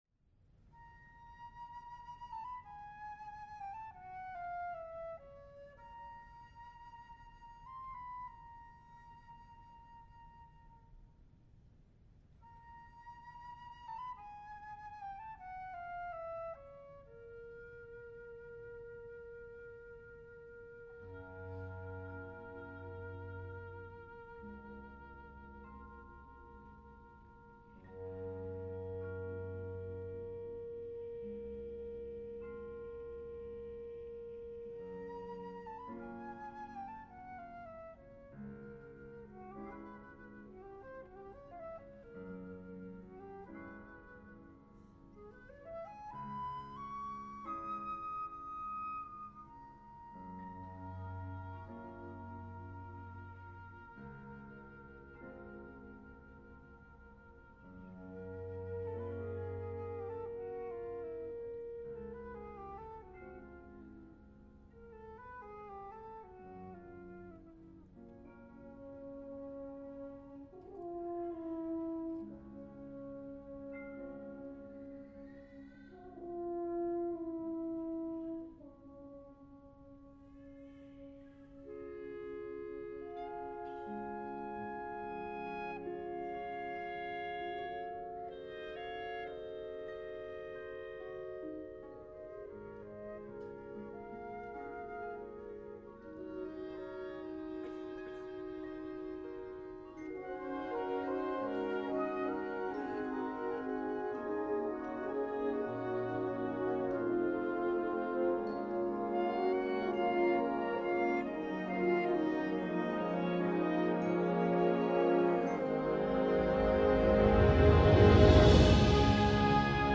フランスの作曲家の名曲の旋律を基にした幻想曲。
編成：吹奏楽
Piano
Harp
Vibraphone